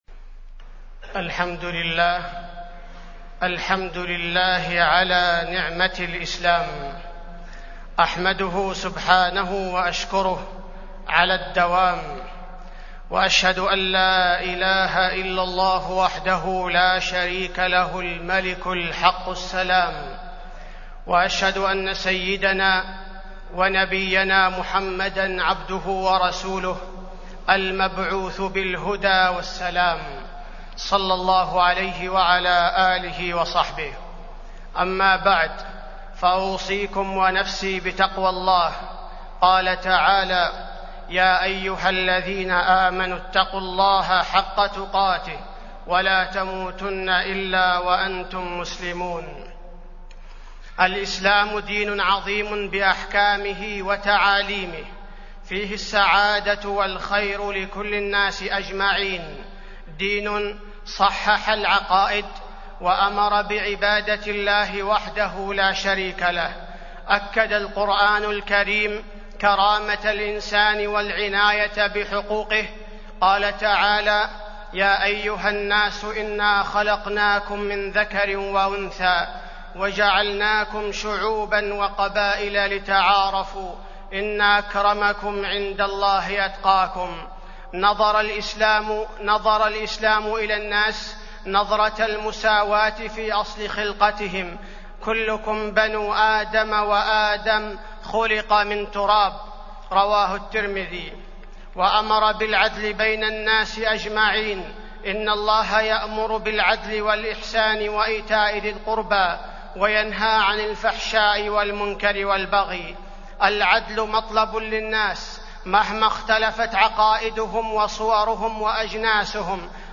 تاريخ النشر ٢٩ شعبان ١٤٢٧ هـ المكان: المسجد النبوي الشيخ: فضيلة الشيخ عبدالباري الثبيتي فضيلة الشيخ عبدالباري الثبيتي منهجية الإسلام